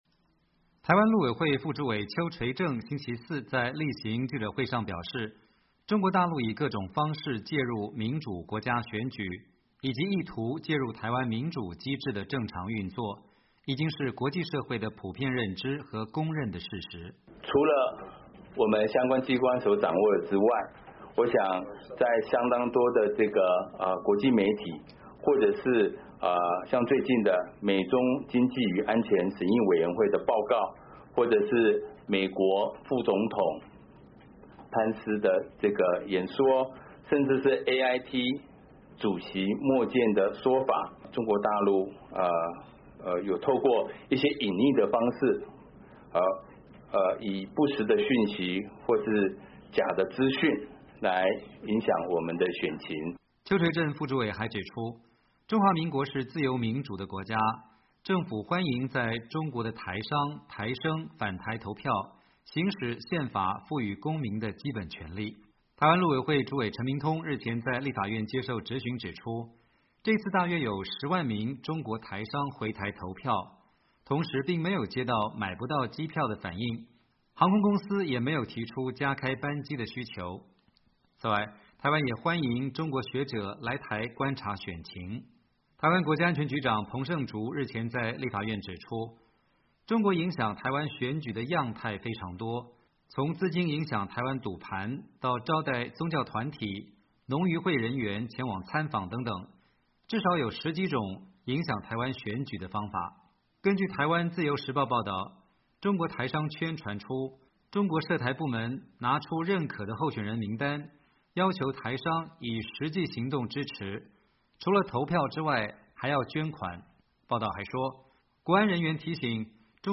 台湾陆委会例行记者会回应中国影响台湾选举报道